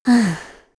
Aselica-Vox-Deny_kr.wav